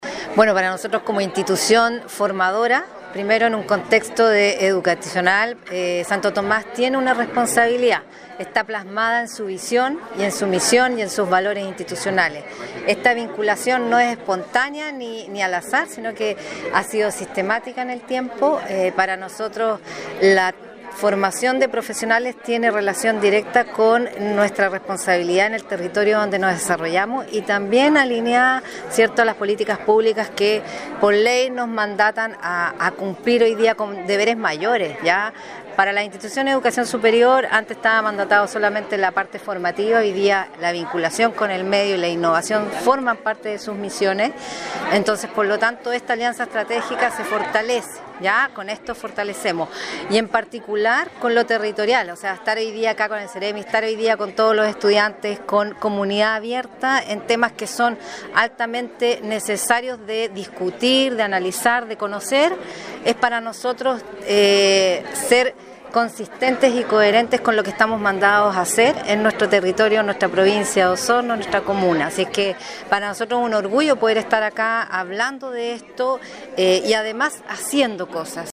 En el Instituto Profesional Santo Tomás Osorno se llevó a cabo ayer el conversatorio titulado “Chile Cuida: Construyendo un País que Protege”, en el marco de la Semana de Servicio Social. Esta significativa actividad tiene como objetivo promover acciones de corresponsabilidad social en el cuidado, contribuyendo así al desarrollo del país.